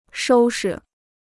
收拾 (shōu shi): to put in order; to tidy up.